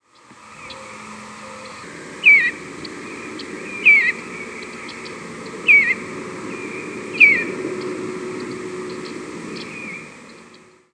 Eastern Bluebird diurnal flight calls
Perched bird with Yellow-rumped Warblers chipping in the background.